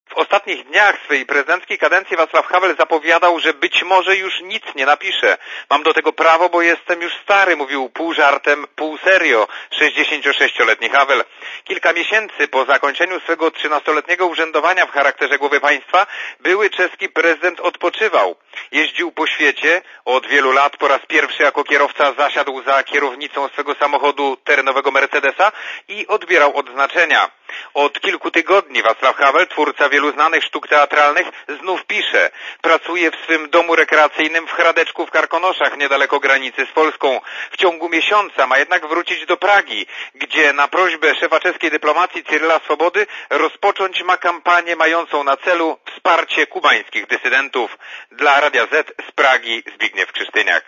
Źródło: PAP (RadioZet) Źródło: (RadioZet) Posłuchaj relacji (200 KB) Oceń jakość naszego artykułu: Twoja opinia pozwala nam tworzyć lepsze treści.